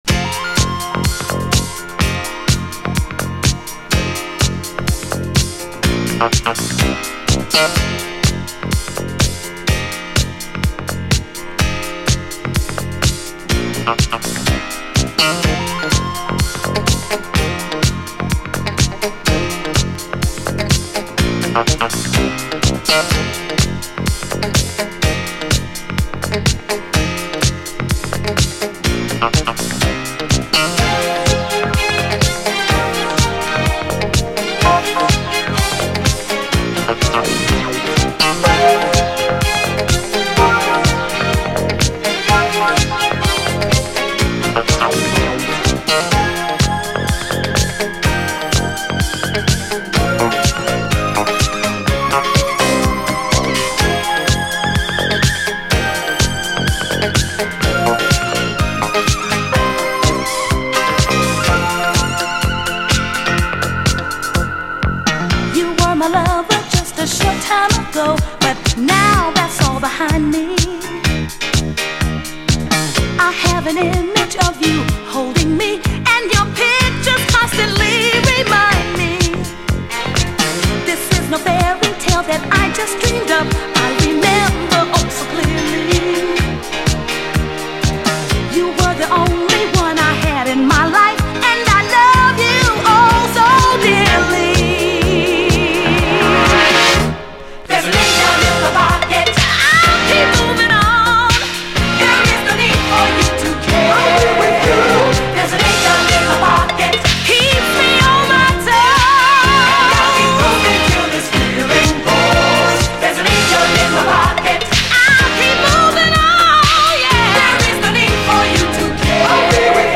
DISCO, 7INCH